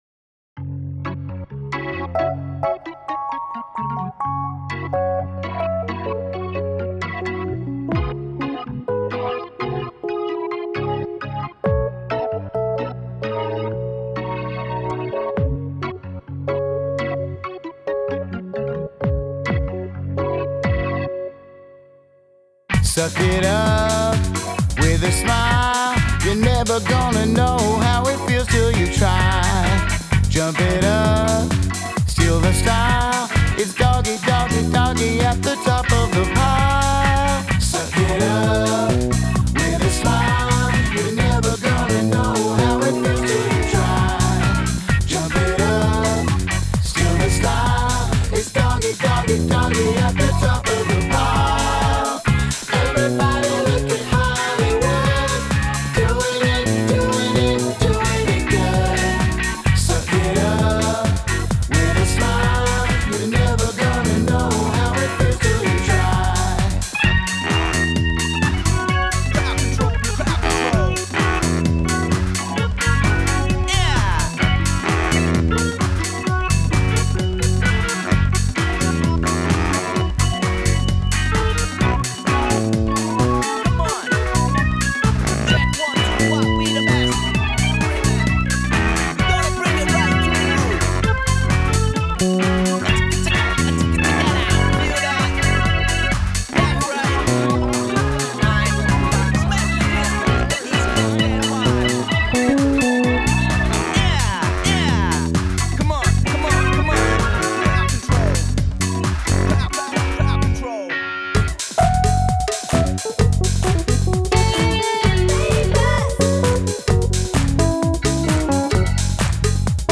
Написал рифф , на эту тему написал инструменталку , но по-моему скучновато и однообразно. Нашёл аккапеллу и соединил с треком. Стало по-веселее, но смущает третья часть, по- моему нет целостности трека. Буду рад замечаниям и советам \ трек толком не сводил, интересует аранжировка/.